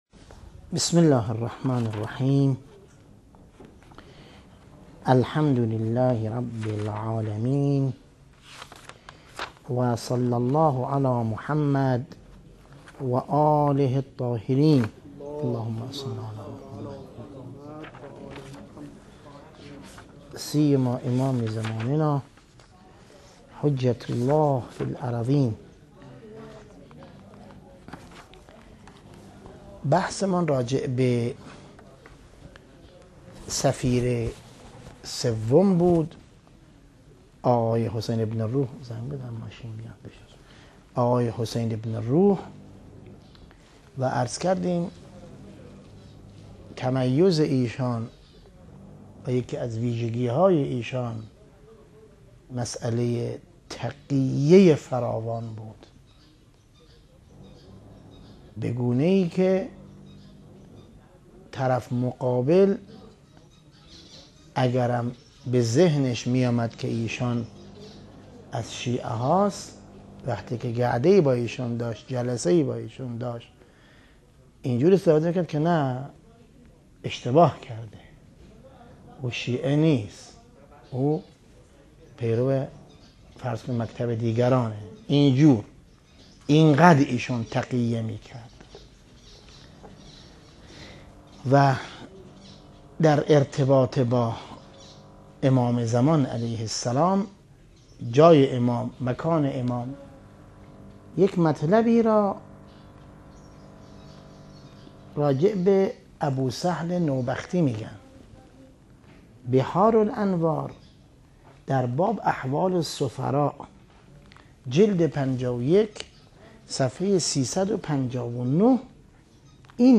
درس مهدویت